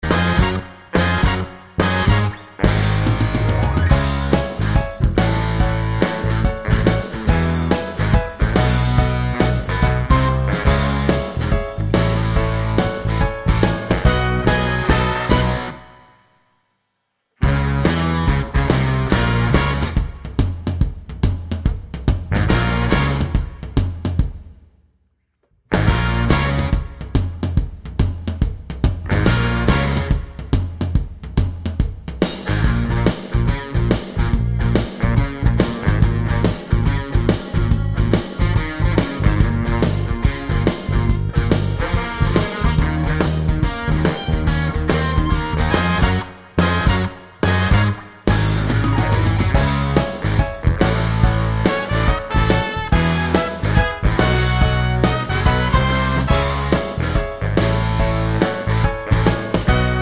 no Backing Vocals Musicals 2:54 Buy £1.50